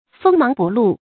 锋芒不露 fēng máng bù lù
锋芒不露发音